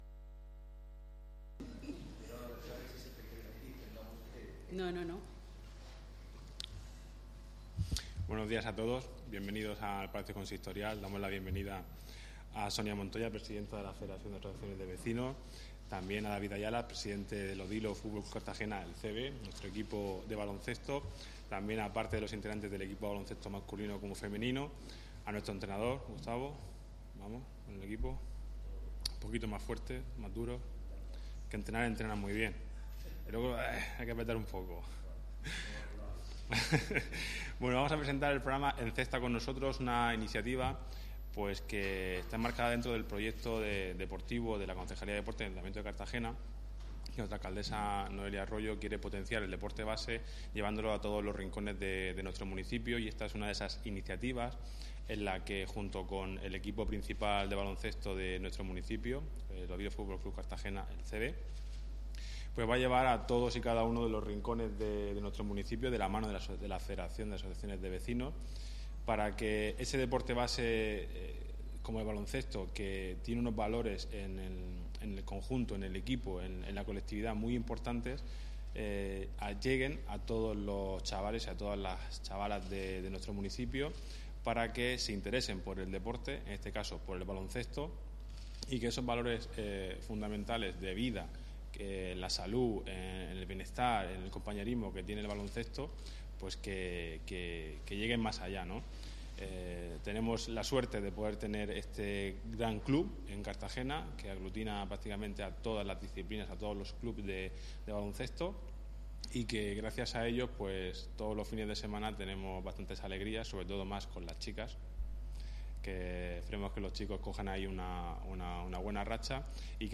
Enlace a Presentación de la campaña Encesta con Nosotros